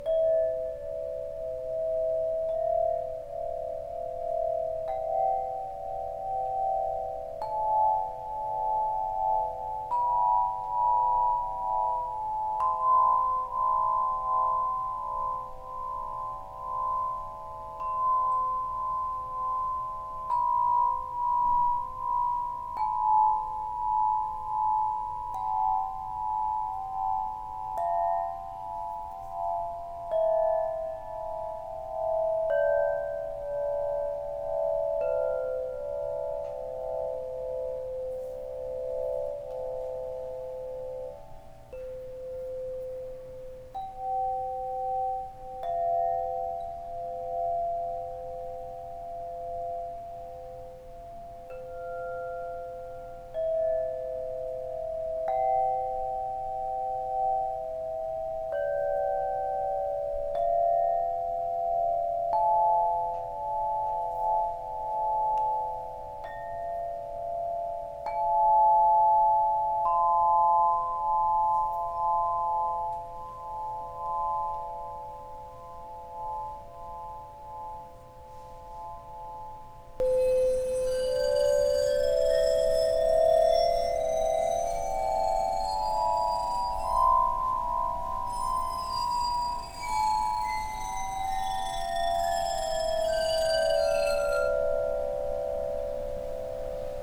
Harpa de Cristal 432Hz
Harpa de Cristal de Quartzo, feita de 8 tubos transparentes e vazados do mais puro vidro de quartzo.
Seus tubos são afinados de Dó a Dó, na escala de Dó Maior na oitava 5.
Afinação 432 Hz.
Ela pode ser tocada badalando os bastões nos tubos de forma intuitiva ou deslizando os dedos molhados com água pelos tubos.
C5 - D5 - E5 - F5 - G5 - B5 - C6
Sua sonoridade é suave e angelical.
harpa432.mp3